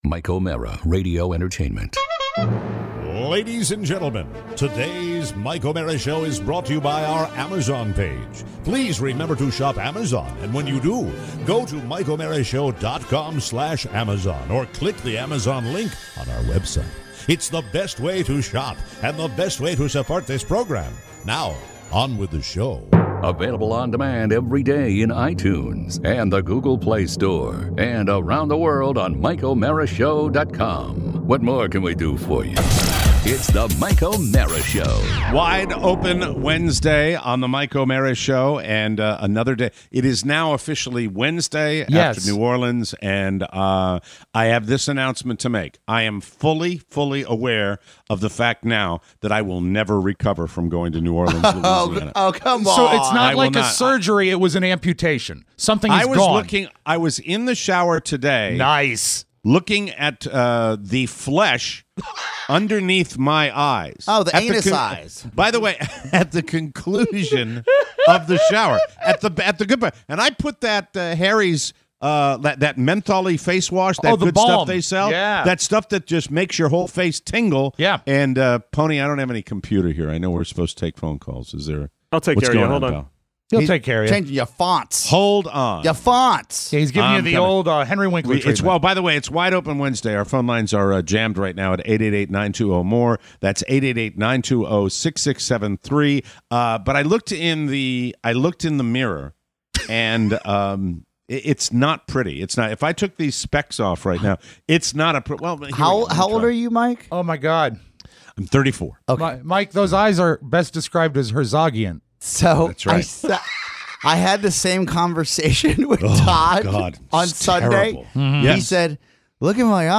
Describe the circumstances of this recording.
Your calls! Plus new Orleans eyes, a stock tip, Virginia turnout… and hotel monkey business.